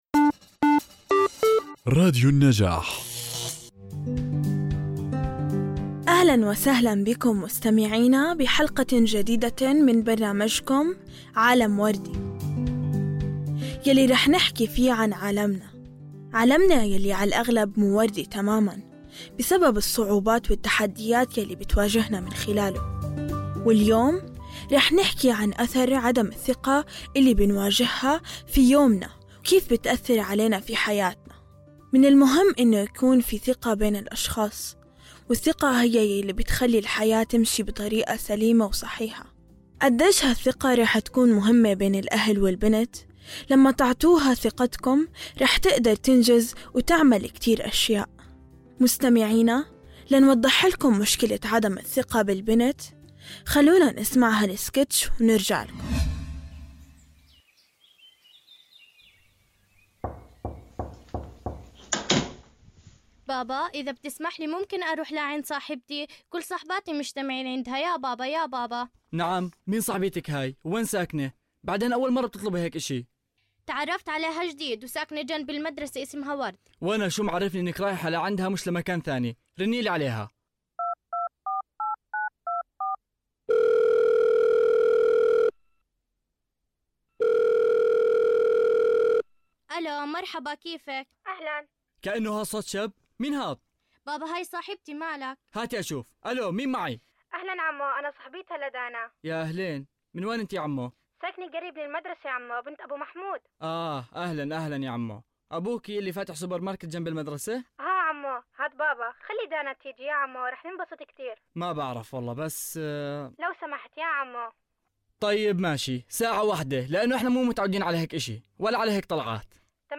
من خلال سكيتش تمثيلي مبتكر ومؤثر، تقدم الحلقة صورة حية للتحديات التي تواجهها البنات بسبب انعدام الثقة بين الأهل والبنات، يشتمل السكيتش على أحداث وقصص حقيقية تم تجسيدها بطريقة تعكس الواقع الذي يعيشه الكثير من الفتيات والنساء، وتبرز اثر انعدام الثقة على صعيد حياتهم النفسية والإجتماعية.
بودكاست علم وردي هو برنامج مميز يقدمه مجموعة من اليافعات المشاركات ضمن مشروع نبادر في مركز  حكاية لتنمية المجتمع المدني، يتميز هذا البودكاست بتضمينه سكيتشات تمثيلية درامية تعكس تجارب الفتيات واليافعات في مواجهة قضايا مثل التمييز بين الذكور والإناث، عدم الثقة بالبنات، تأثير السوشل ميديا على حياتهن، العنف ضد البنات والعصبية.